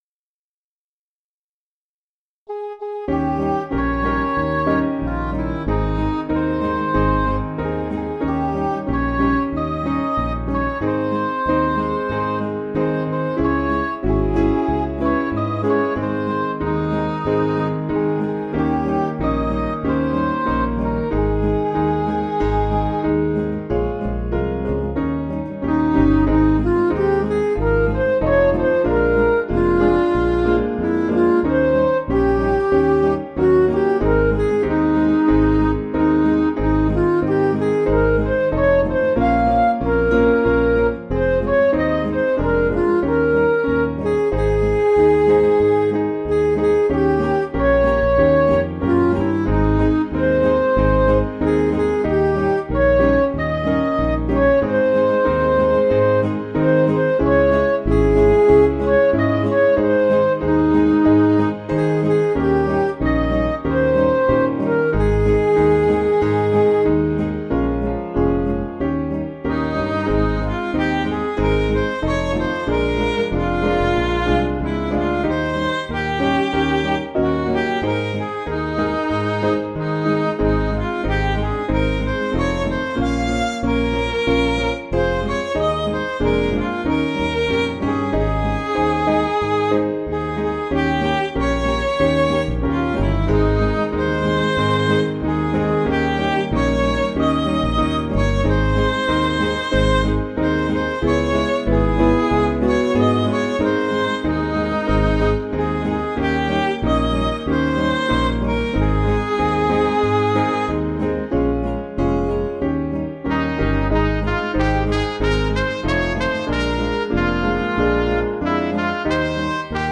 Piano & Instrumental
(CM)   3/Ab